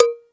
COWBELL.wav